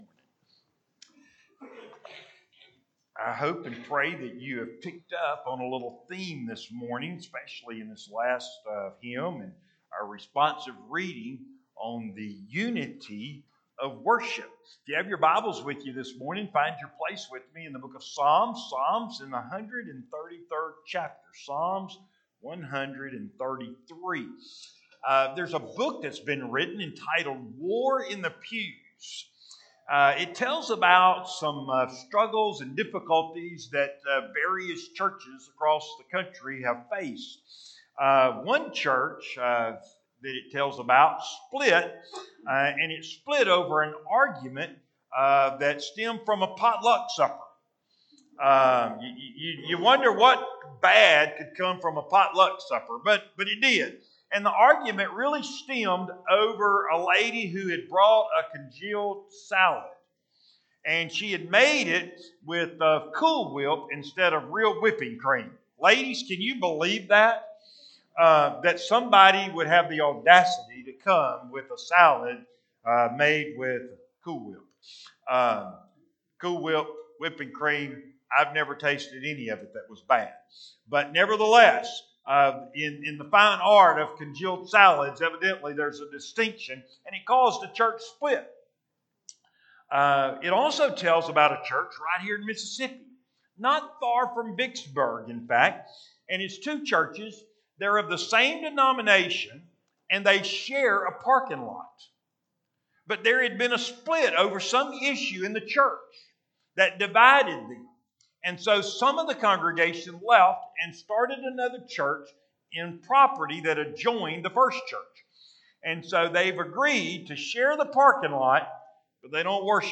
Audio Sermons: Videos of service can be seen on Facebook page - Trinity Baptist Church